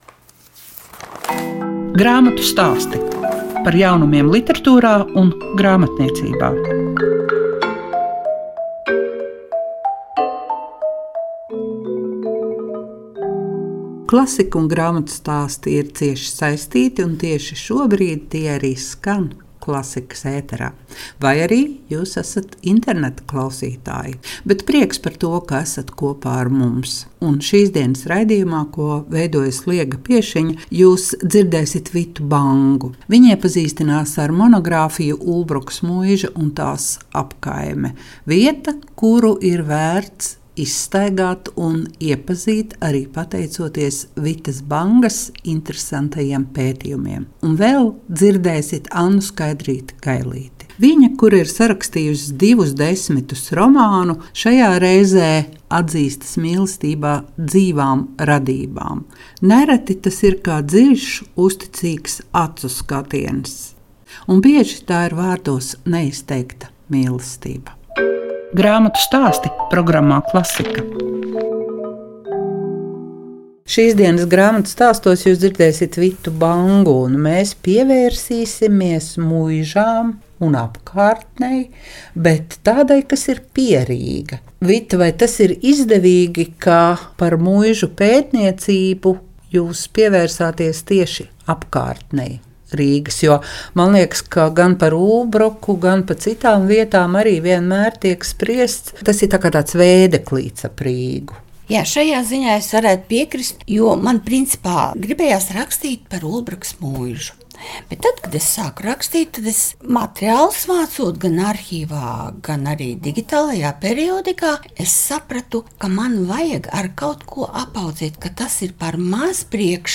Lielums vienmēr sākas ar mazumu, tā varētu skaidrot vietas attīstību. Raidījumā –plašāka saruna